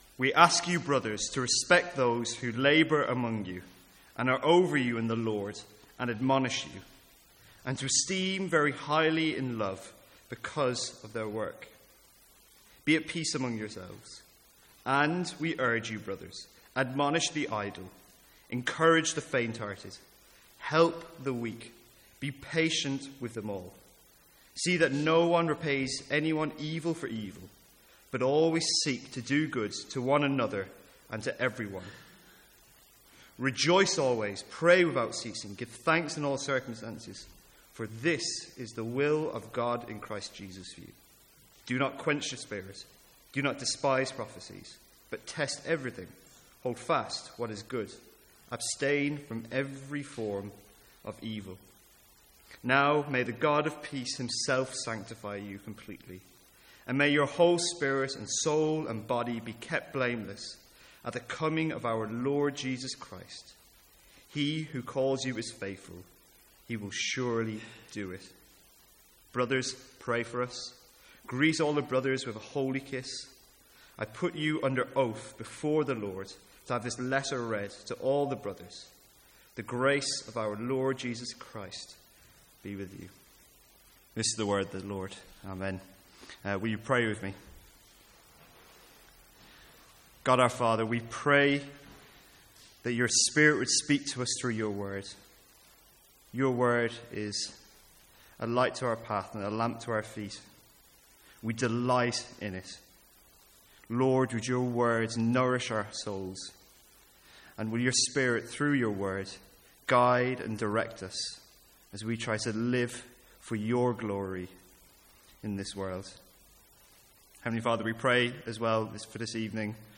Sermons | St Andrews Free Church
From the Sunday evening series in 1 Thessalonians.